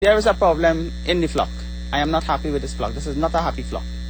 Soundbyte van de dag!
Middenin een NOS Nieuws-item over vogelgriep, tijdens een reportage over het mogelijk uitbreken daarvan op Trinidad, is daar plotseling die helaas naamloze Ambtenaar, van het Ministerie Van Landbouw aldaar.